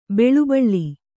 ♪ beḷu baḷḷi